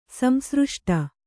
♪ samsřṣṭi